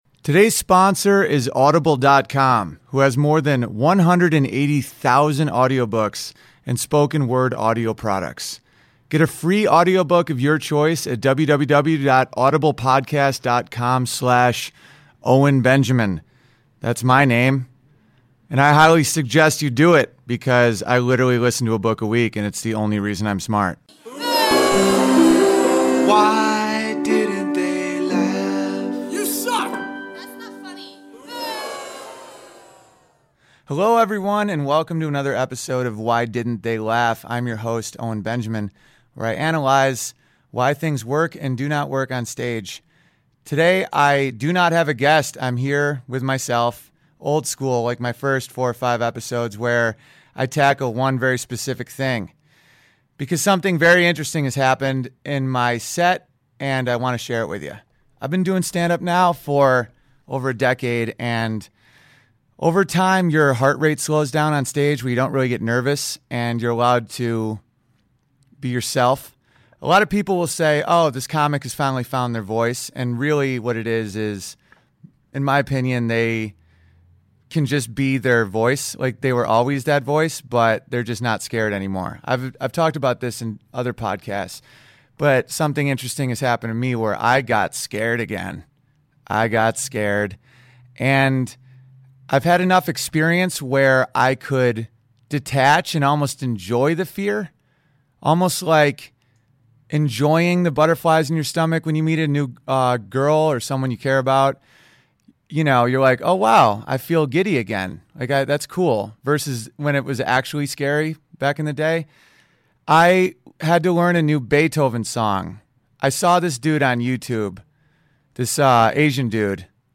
Listen to me freeze up attempting a new piano song live. No guest this week. Just me against a new joke.